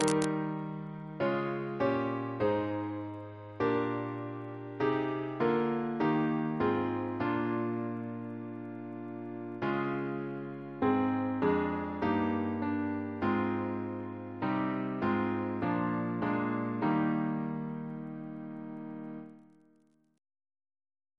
Double chant in E♭ Composer: Sir Edward C. Bairstow (1874-1946), Organist of York Minster Reference psalters: ACB: 211; ACP: 32 149; H1982: S443; RSCM: 13